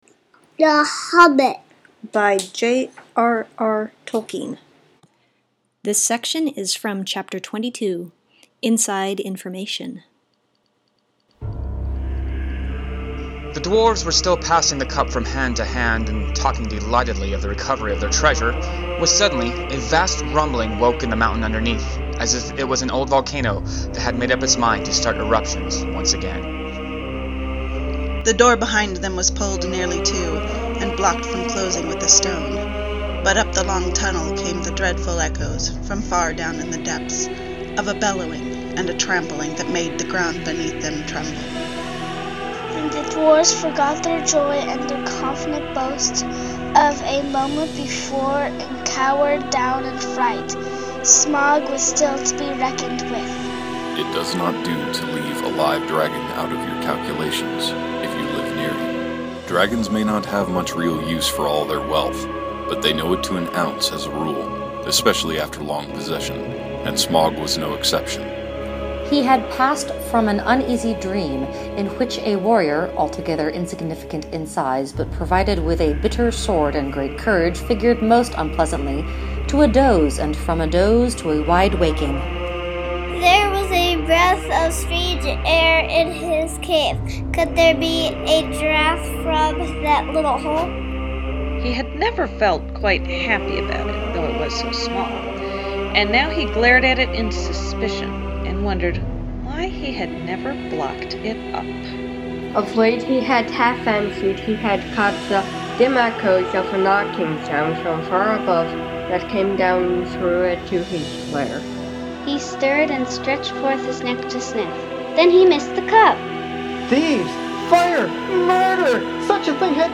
multi-user read-alouds of literature excerpts